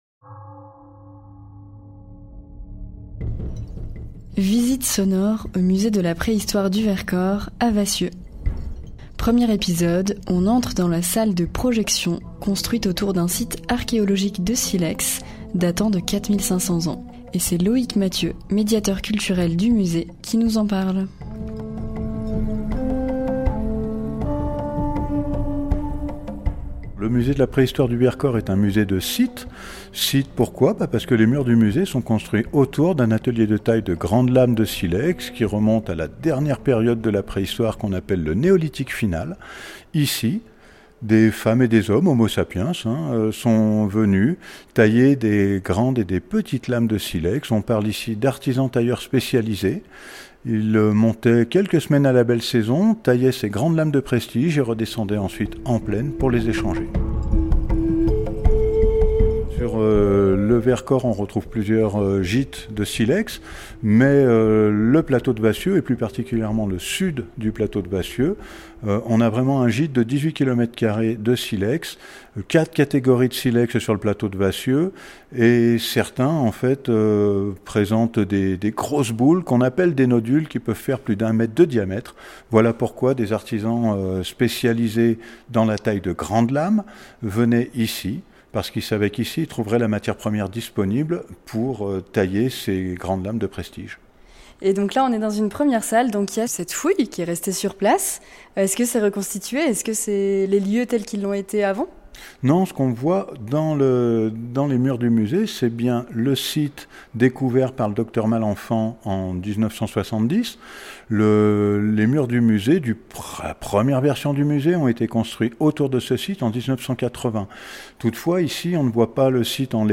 Radio Royans Vercors vous propose une visite sonore du Musée de la Préhistoire du Vercors, à Vassieux. Un voyage dans le temps pour découvrir le silex et les manières dont les femmes et les hommes de la Préhistoire ont parcouru et habité le massif depuis plus de 50 000 ans.